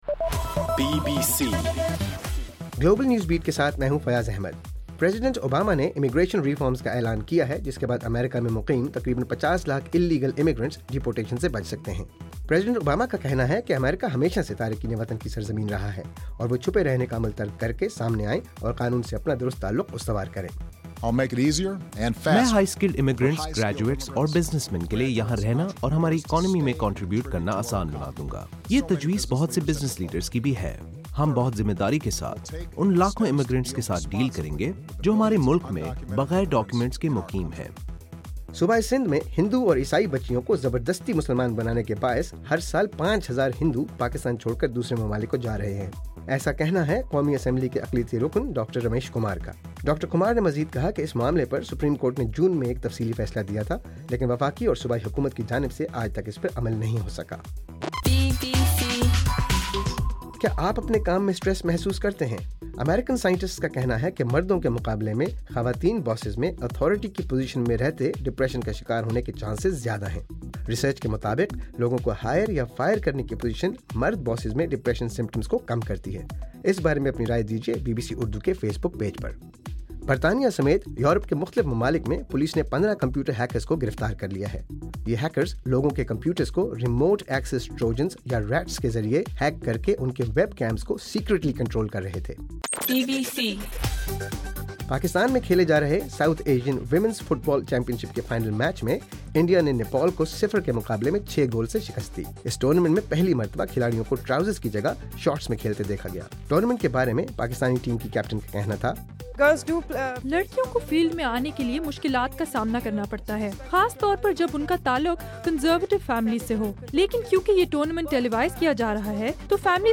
نومبر 22: صبح 1 بجے کا گلوبل نیوز بیٹ بُلیٹن